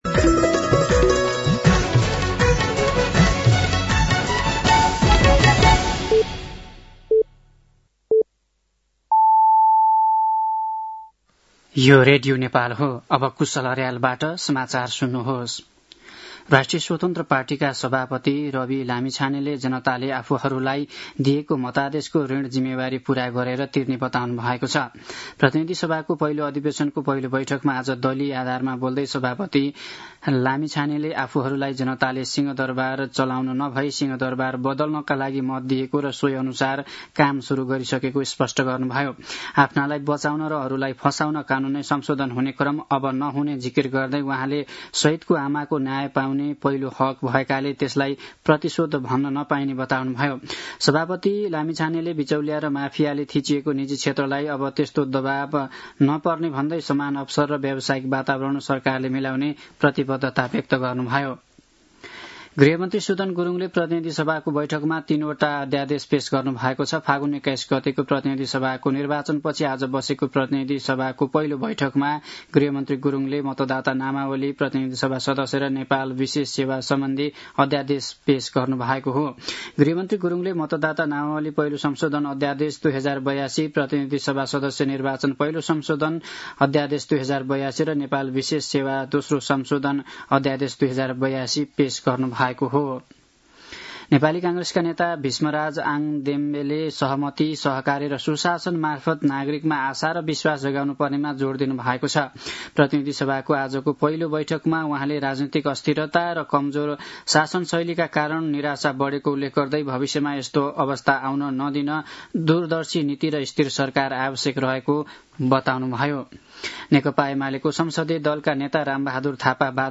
साँझ ५ बजेको नेपाली समाचार : १९ चैत , २०८२
5-pm-news.mp3